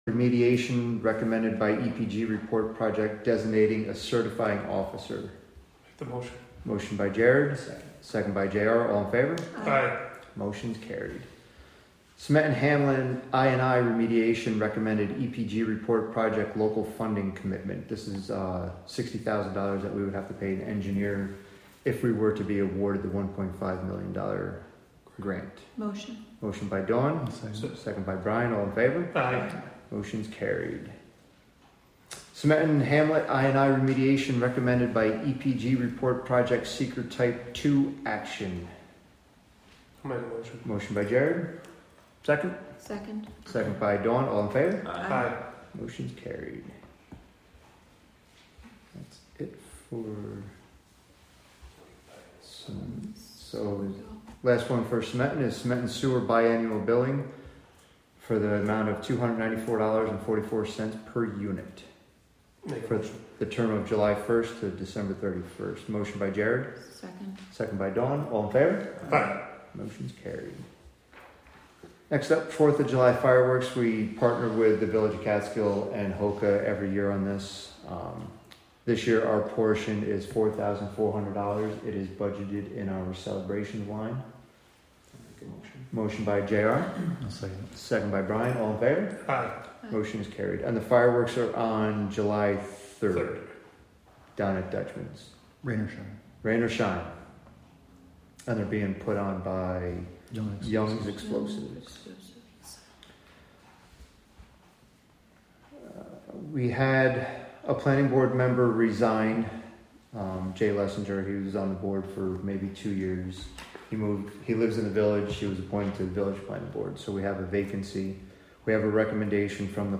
Live from the Town of Catskill: June 18, 2025 Catskill Town Board Meeting (Audio)